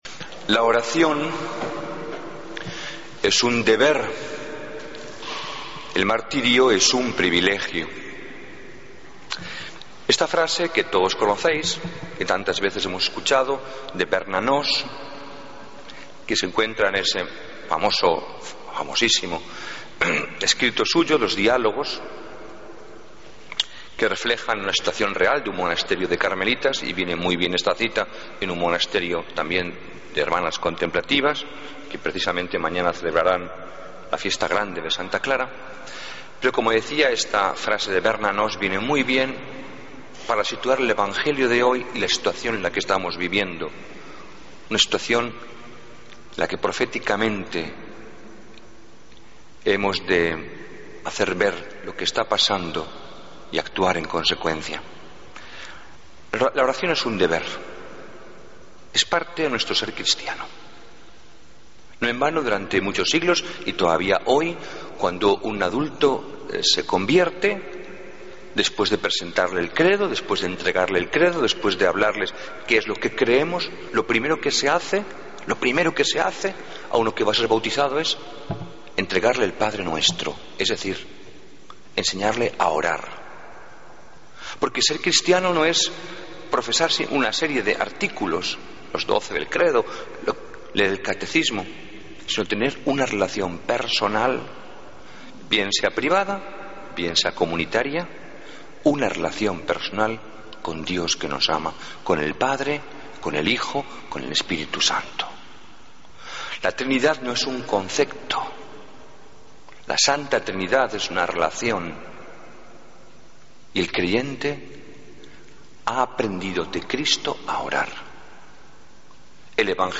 Homilía del 10 de agosto